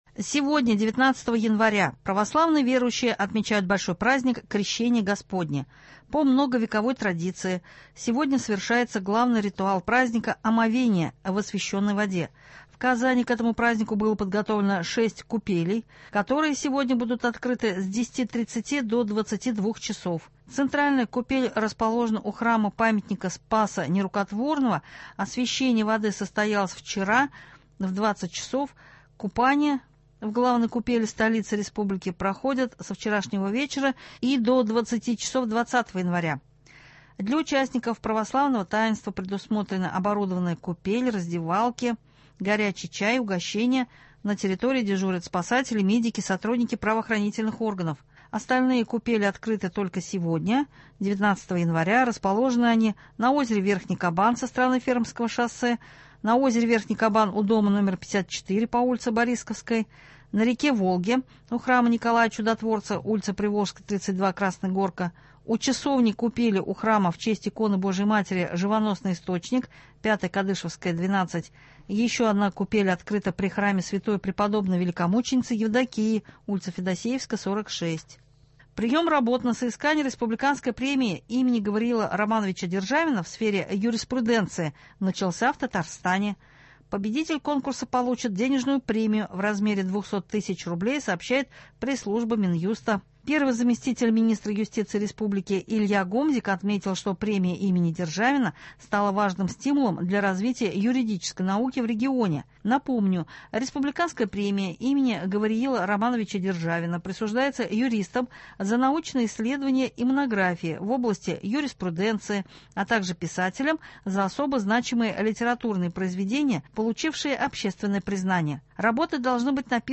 Новости (19.01.26)